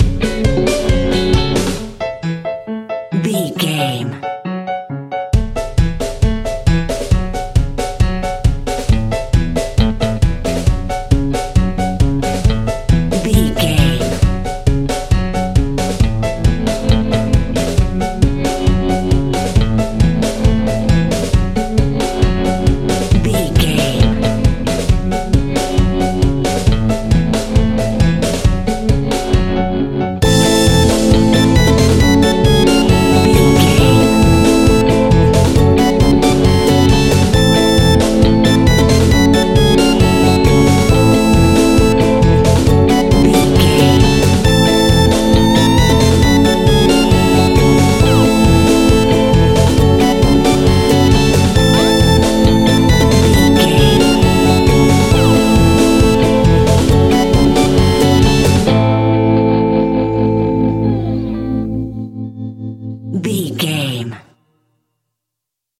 Aeolian/Minor
tension
ominous
eerie
piano
drums
bass guitar
synthesizer
spooky
horror music